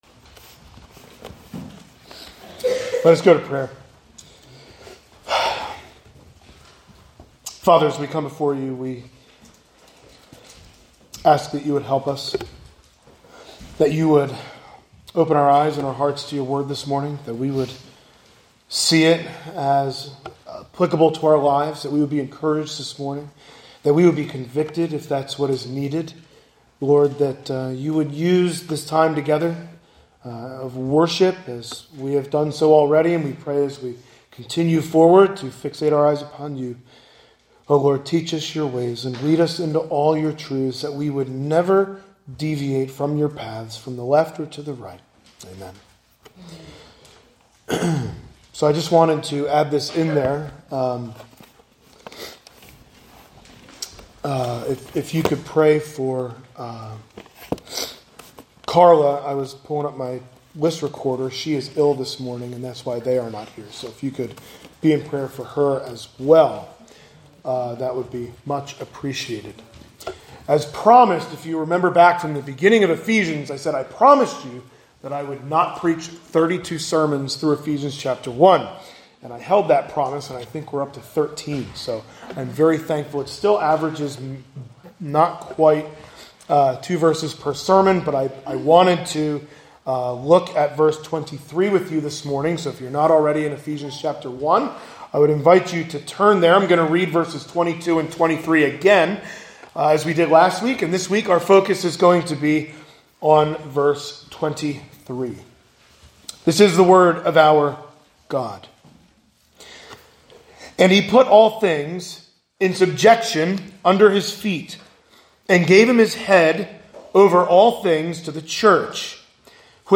Sunday Morning Sermons | Zionsville Bible Fellowship Church
This sermon looks at the final facet of Paul's prayer.